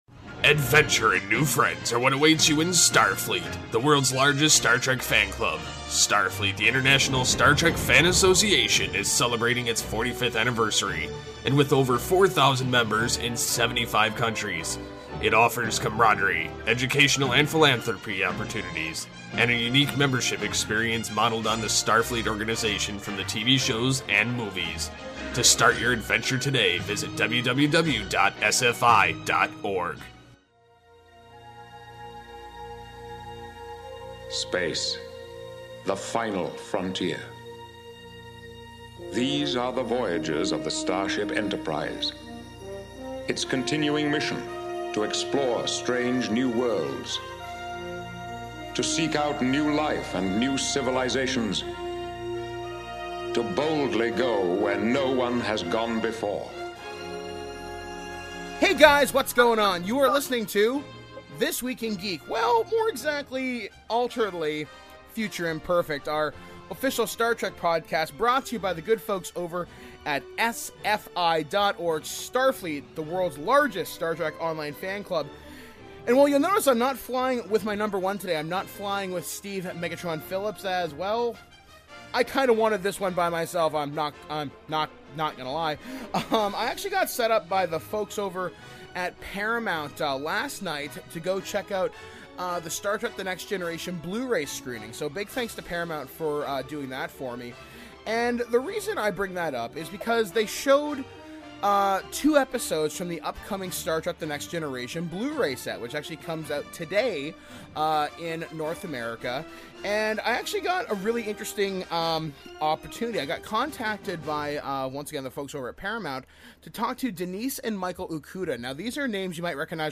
Interviews – Denise & Michael Okuda